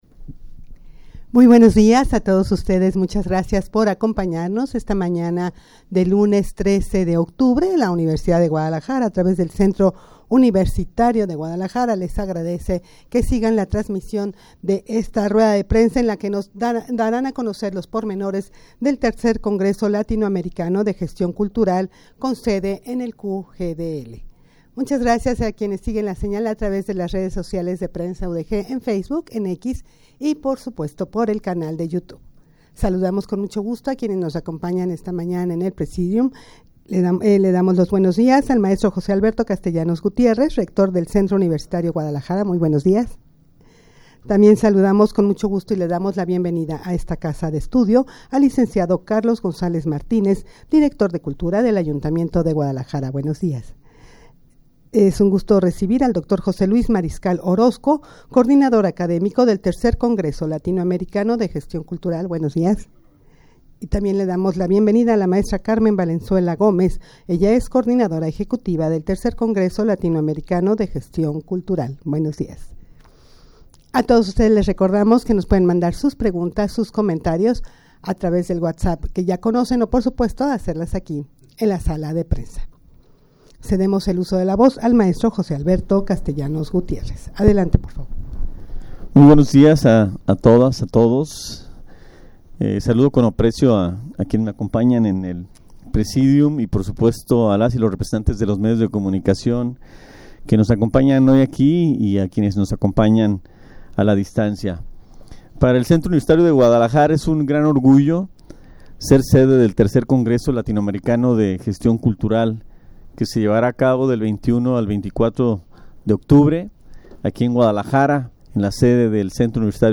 Audio de la Rueda de Prensa
rueda-de-prensa-para-presentar-el-3er-congreso-latinoamericano-de-gestion-cultural-con-sede-en-el-cugdl.mp3